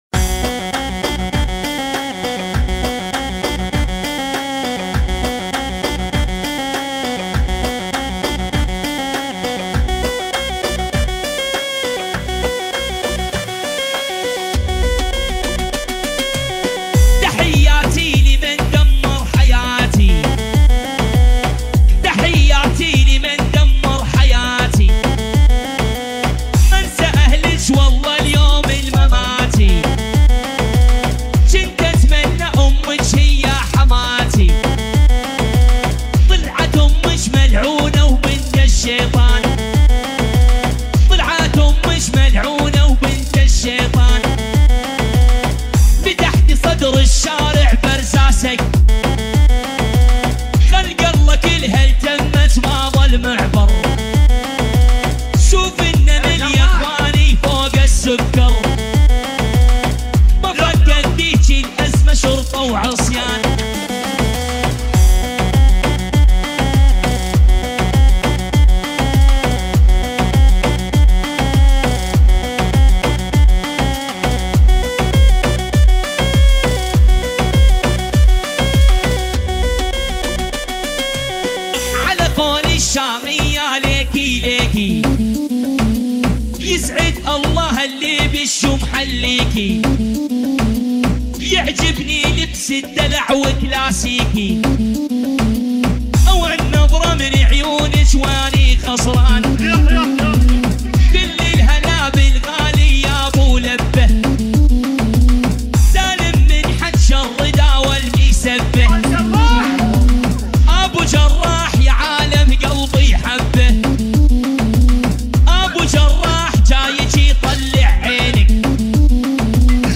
2022 Arabic Remix